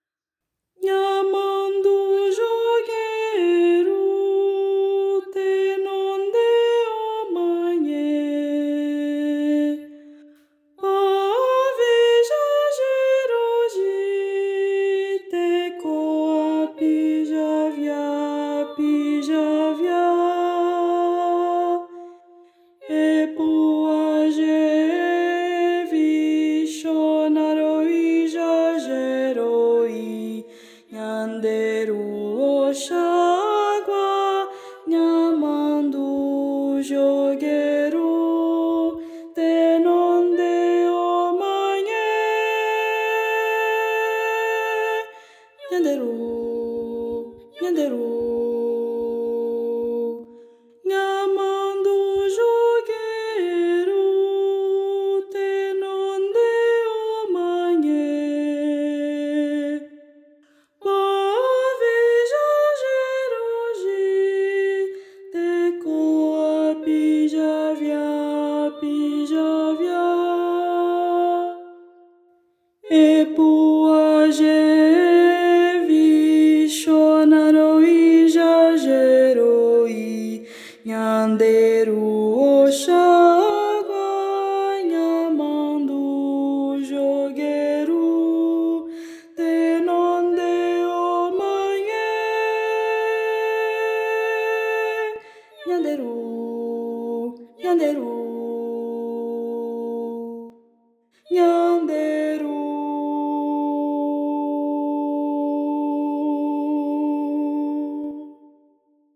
Voz Guia 3